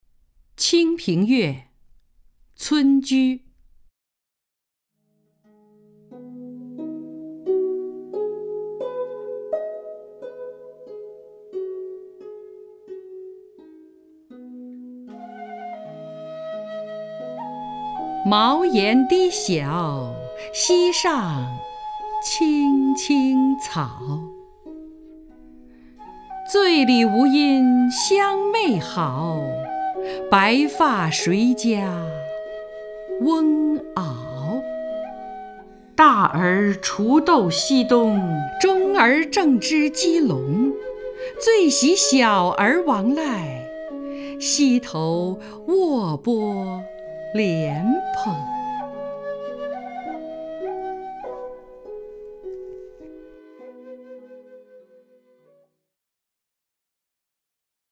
张筠英朗诵：《清平乐·村居》(（南宋）辛弃疾)
名家朗诵欣赏 张筠英 目录